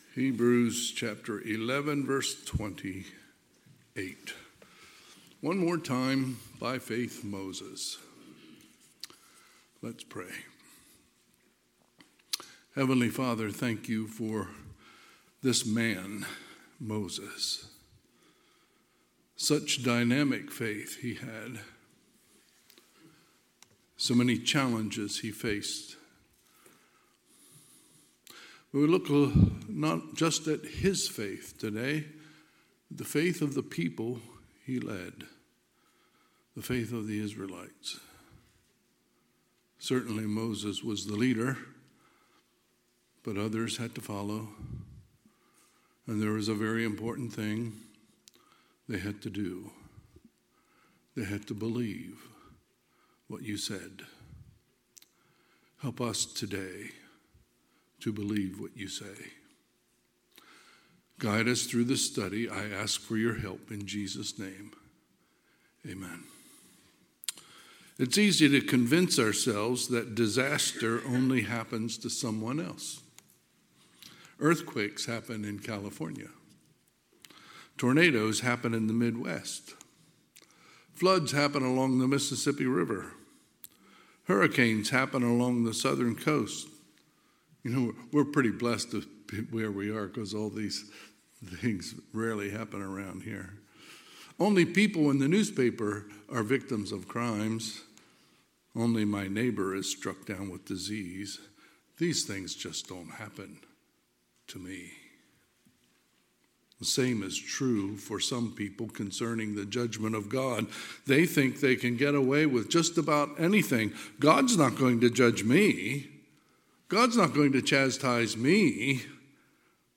Sunday, October 27, 2024 – Sunday AM